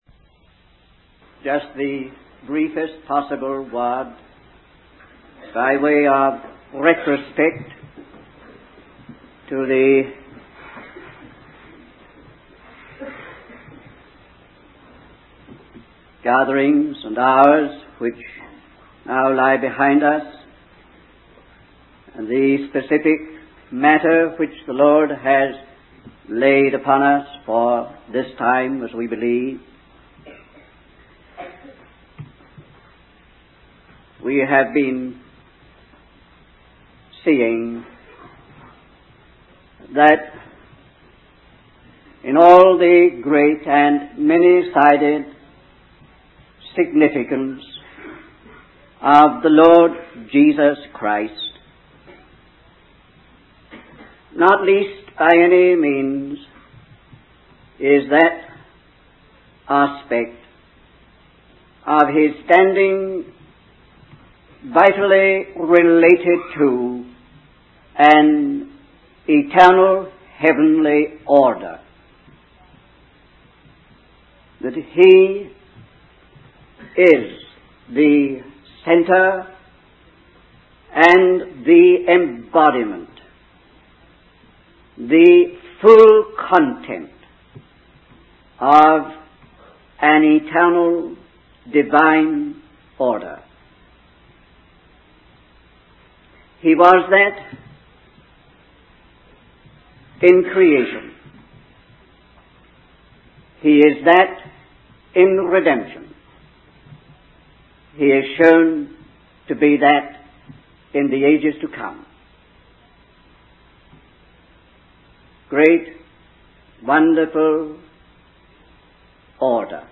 In this sermon, the speaker discusses the parable of casting something into the waters and the waters being healed.